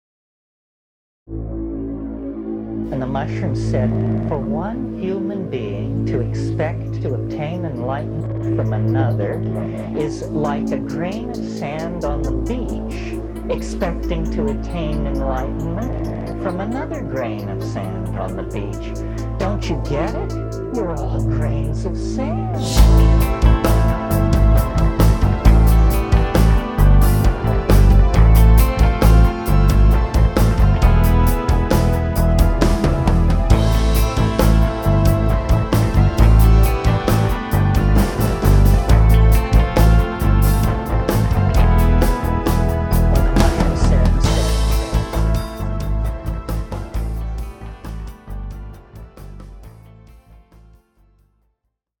Style 3: Psychedelia
Instead, it'll drone on as a pedal tone to create a floatier and less active low end. We'll also change our orchestration to include some trippier-sounding instruments, like synths and pads.
And of course, no psychedelic track is complete without the soothing sounds of Terence McKenna's papal voice.
psychedelic-andalusian.mp3